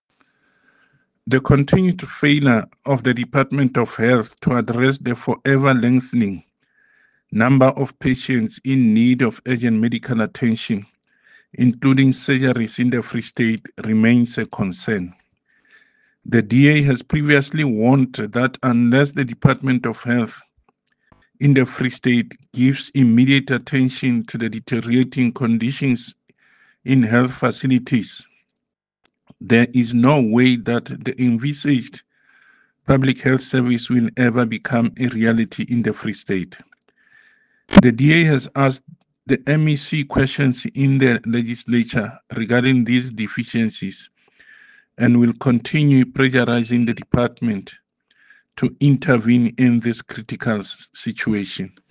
Sesotho soundbites by David Masoeu MPL and Afrikaans soundbite by Werner Pretorius MPL